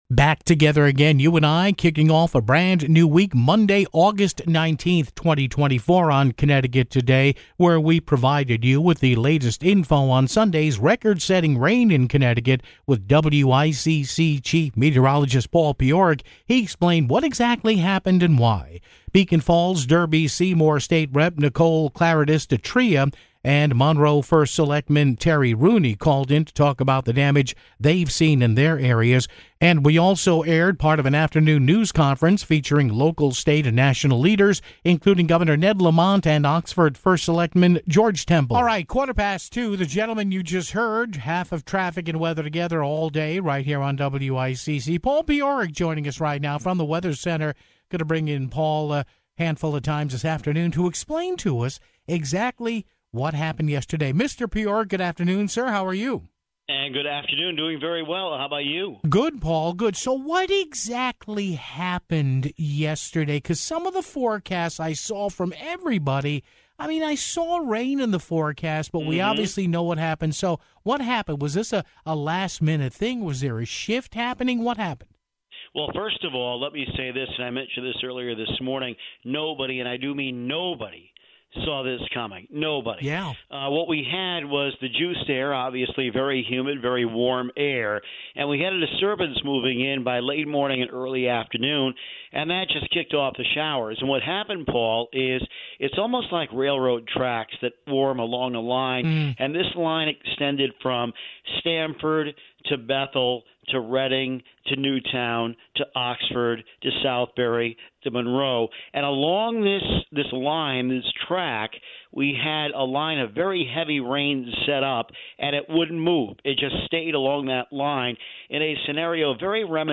Beacon Falls-Derby-Seymour State Rep. Nicole Klarides-Ditria (04:14) and Monroe First Selectman Terry Rooney (09:54) called in to talk about the damage they've seen. We also aired part of an afternoon news conference featuring local, state and national leaders, including Governor Ned Lamont and Oxford First Selectman George Temple (13:29)